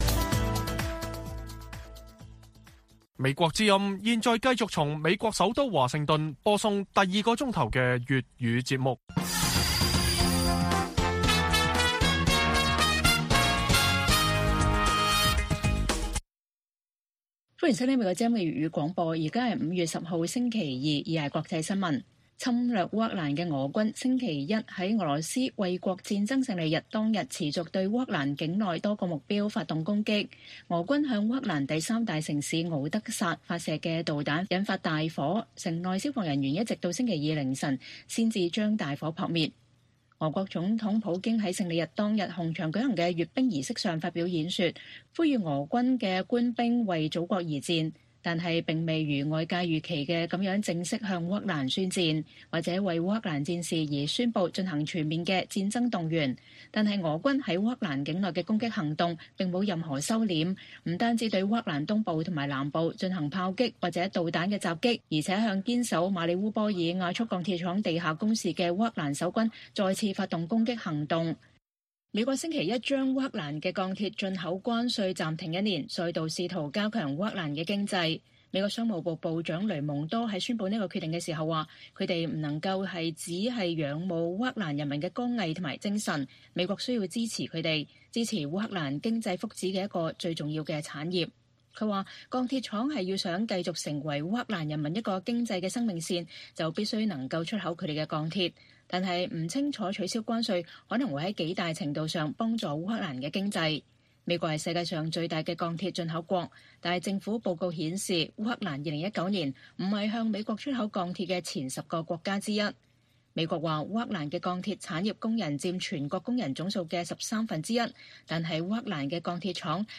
粵語新聞 晚上10-11點: 侵烏俄軍“勝利日”不停手，向敖德薩發射導彈擊中民宅和購物中心引發大火